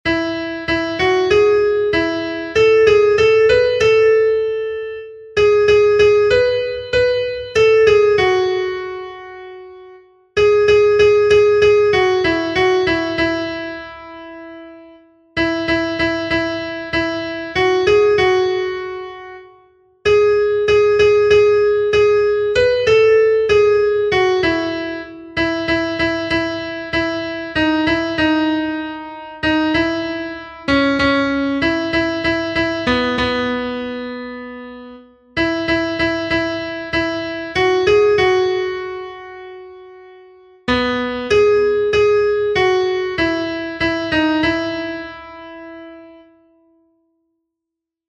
Kontakizunezkoa
Zortziko handia (hg) / Lau puntuko handia (ip)